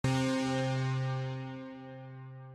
Piano11.mp3